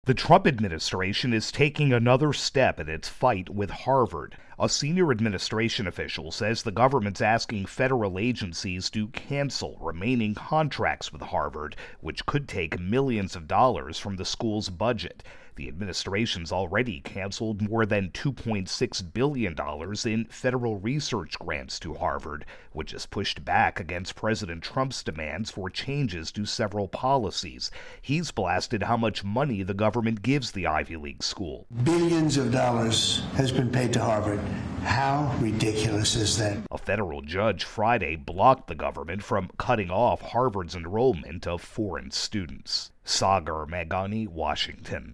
reports on the Trump administration's latest step in its fight with Harvard.